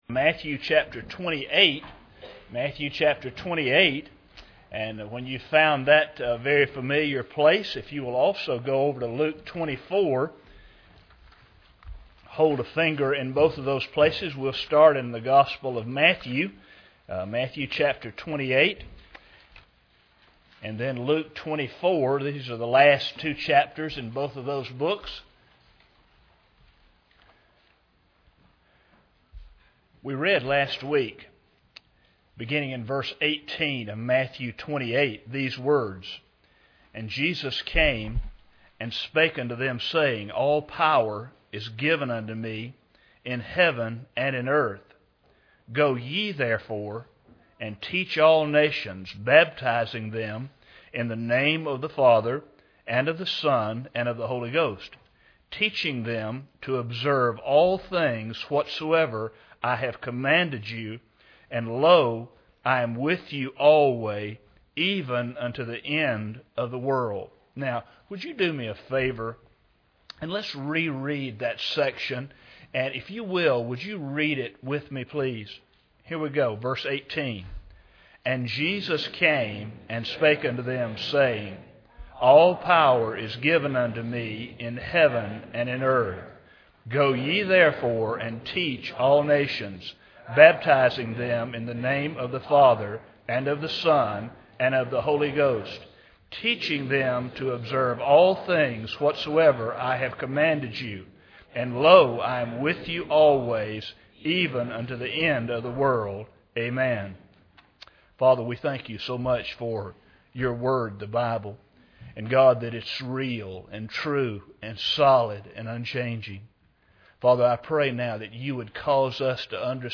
Matthew 28:19-20 Service Type: Sunday Morning Bible Text